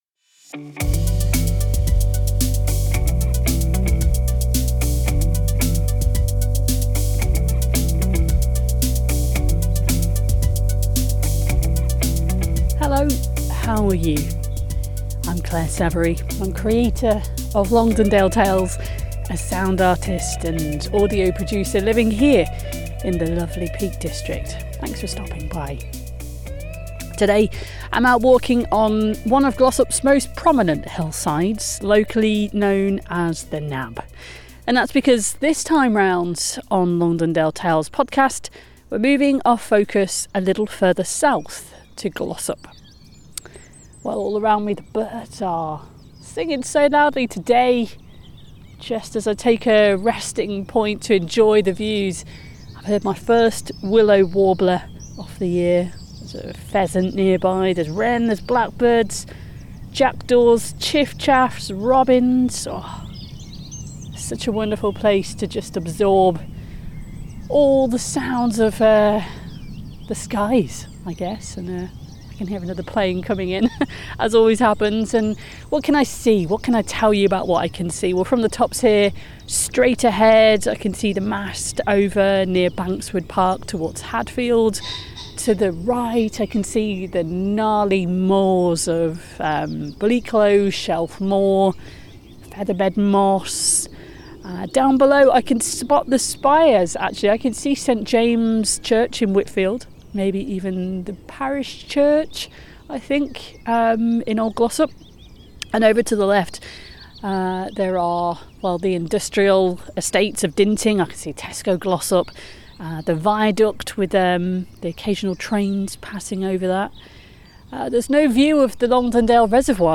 LT-SOUNDS-WHY-LISTEN-mixdown.mp3